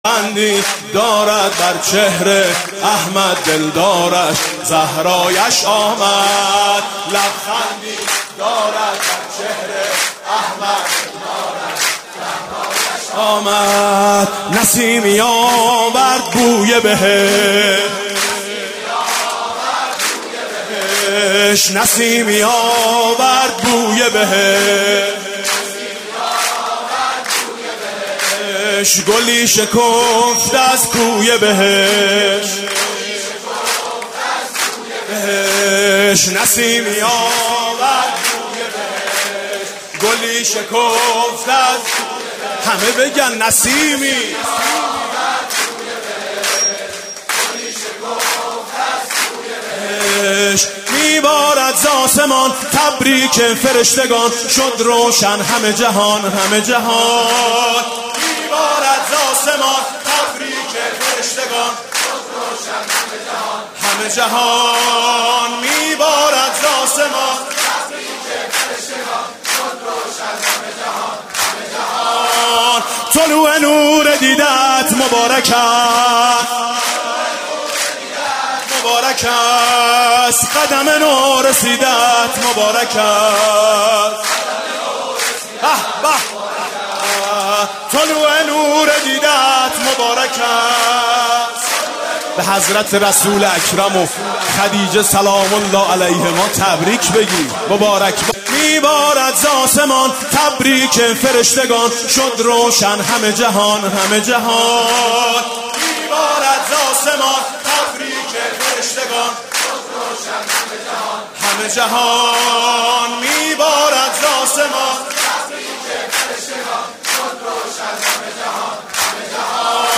مولودی خوانی دو زبانه میثم مطیعی به مناسبت ولادت حضرت زهرا (س)